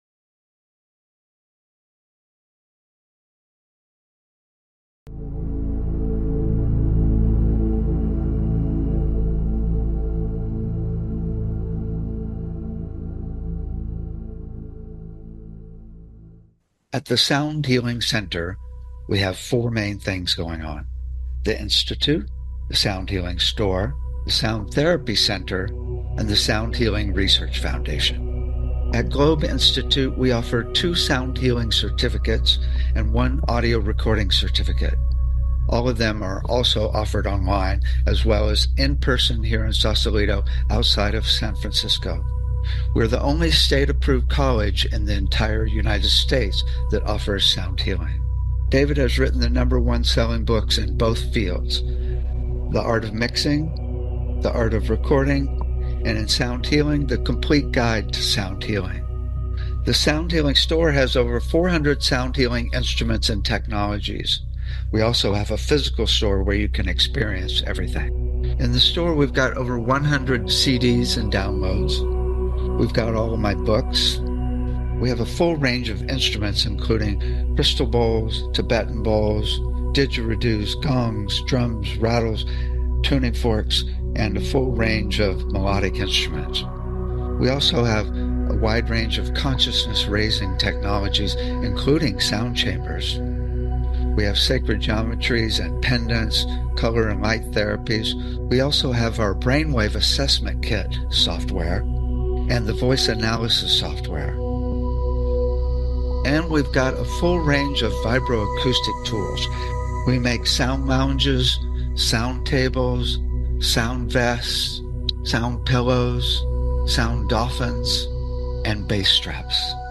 The show is a sound combination of discussion and experience including the following topics: Toning, Chanting and Overtone Singing - Root Frequency Entrainment - Sound to Improve Learning -Disabilities - Using Sound to Connect to Spirit - Tuning Fork Treatments - Voice Analysis Technologies - Chakra Balancing - Sound to Induce Desired States of Being - Tibetan/Crystal Bowl Massage - Electronic Nerve Stimulation - Water Sound Infusion Systems - Sound Visualization systems - Infratonics Holographic Sound - Scalar wave EESystem Drumming and Rhythm - HydroAcoustic Therapy - Neurophone Bio-Tuning - Sound Surgery - Cymatics Frequency based therapeutic devices - VibroAcoustic Sound Chairs and Tables Be a guest on this podcast Language: en-us Genres: Education , Kids & Family Contact email: Get it Feed URL: Get it iTunes ID: Get it Get all podcast data Listen Now...